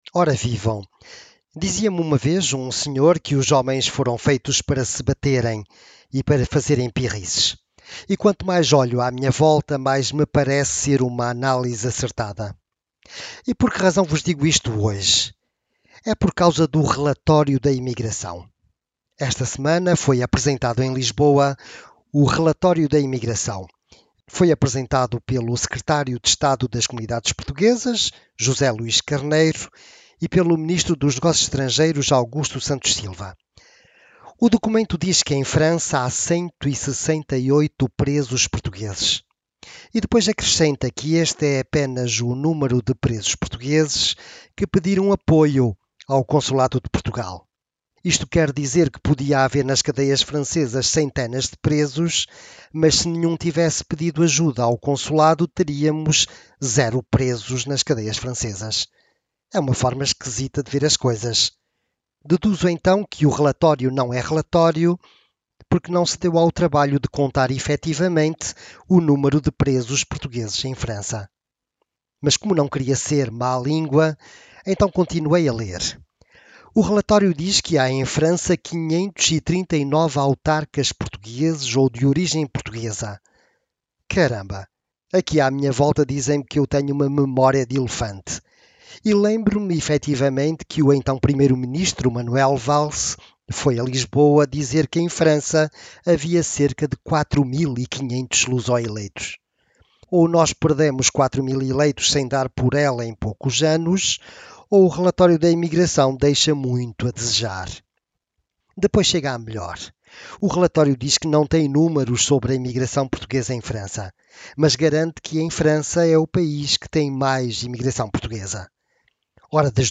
Um relatório oficial da emigração, que deixa muito a desejar. Sobre os portugueses de França não é um relatório, é a confusão total. Ouça aqui a crónica de opinião